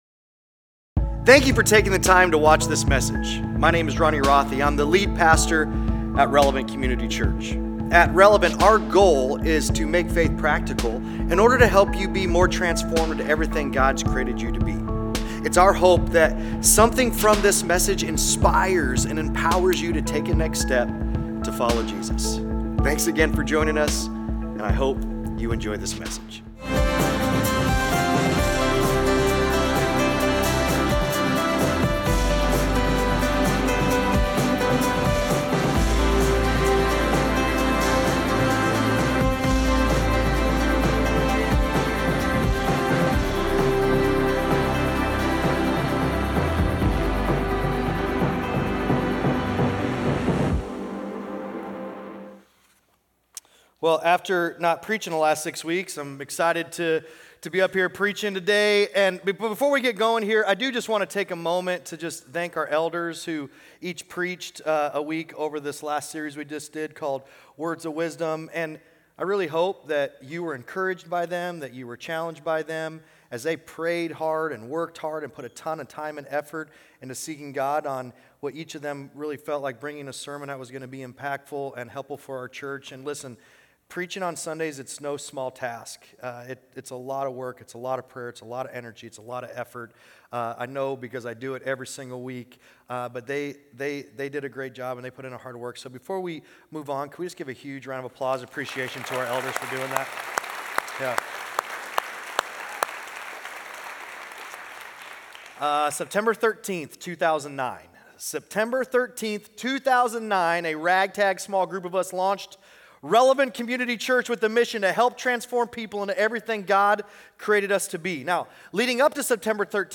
Weekly sermons from Relevant Community Church in Elkhorn, NE.